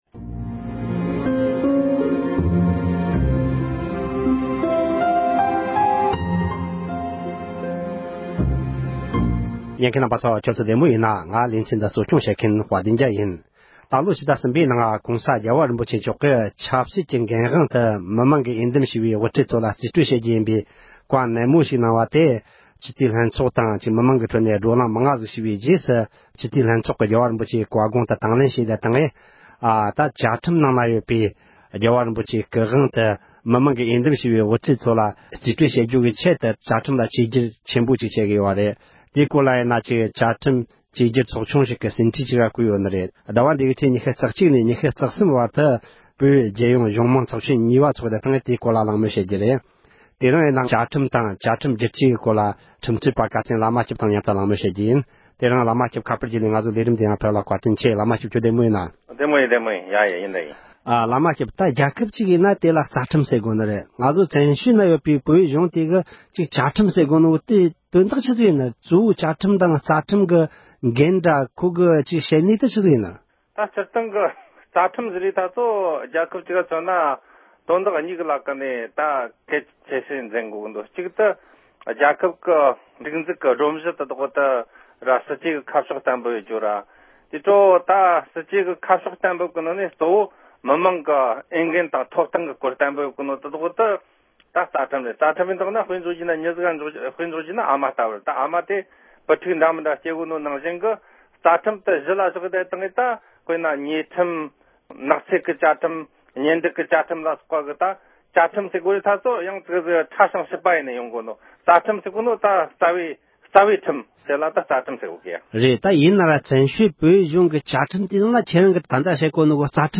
གླེང་མོལ